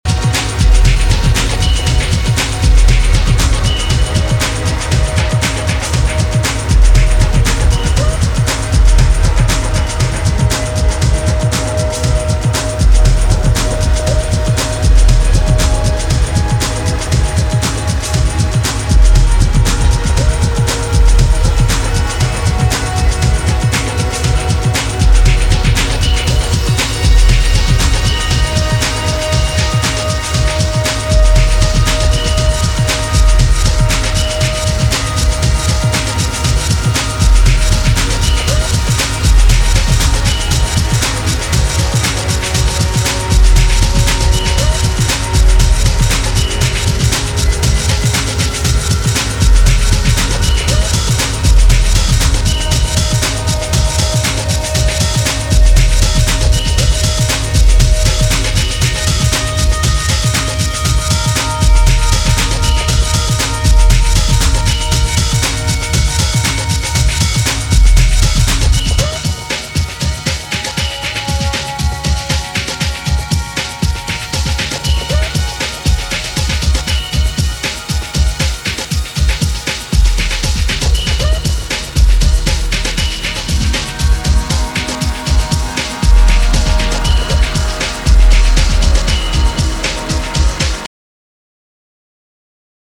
House Techno Breaks